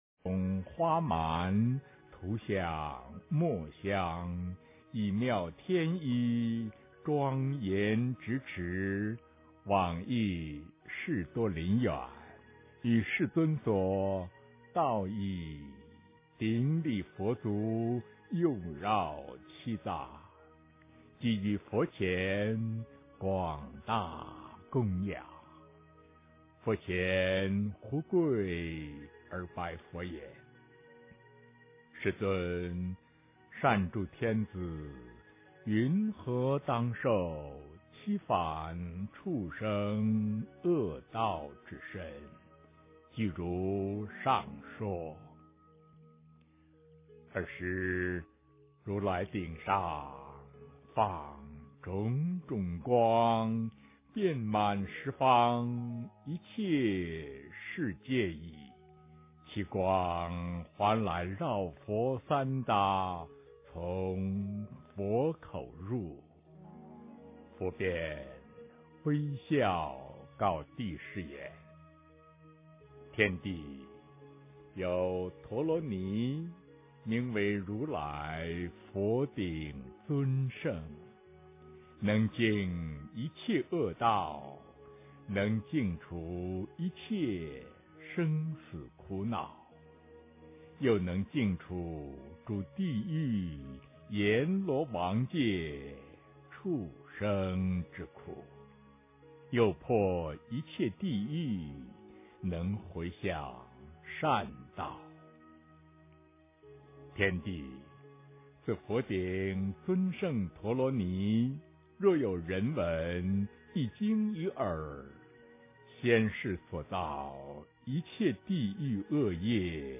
诵经
佛音 诵经 佛教音乐 返回列表 上一篇： 佛说拔除罪障咒王经 下一篇： 普贤行愿品 相关文章 职场29六和敬的意义--佛音大家唱 职场29六和敬的意义--佛音大家唱...